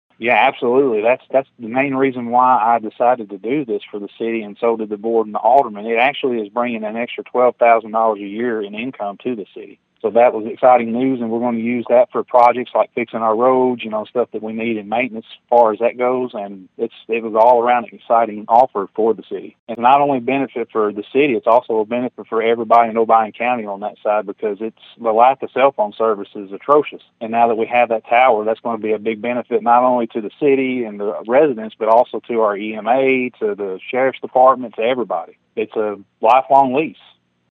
Mayor Lewis said the tower selection site brings needed benefits to Woodland Mills and the county.(AUDIO)